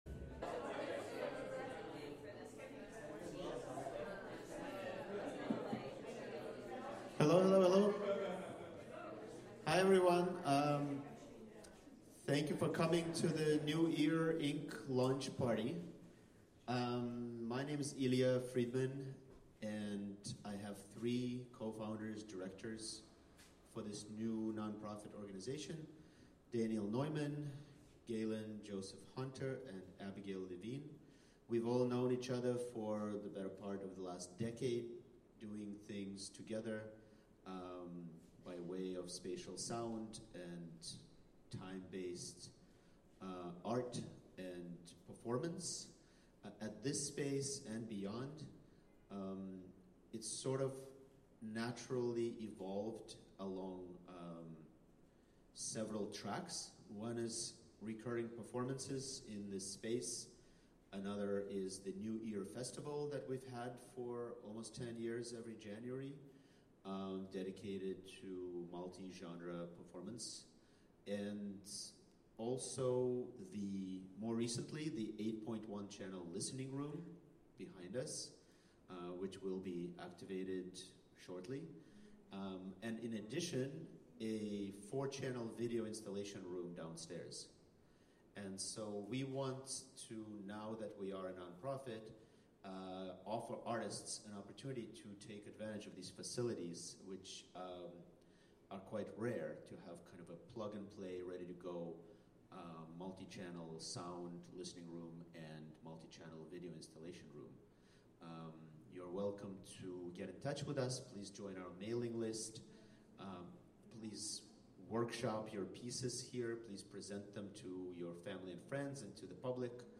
Launch Party
in the 8-channel listening room